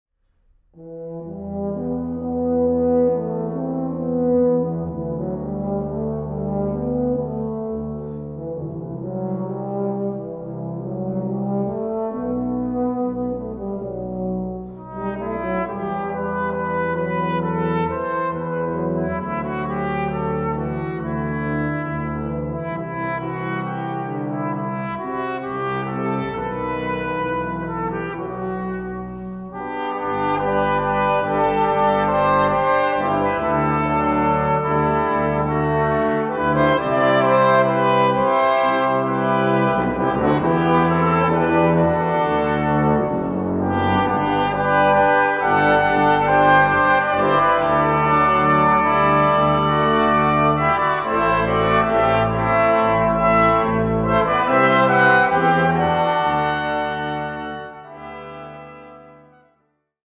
Aufnahme: Jesus-Christus-Kirche Berlin-Dahlem, 2011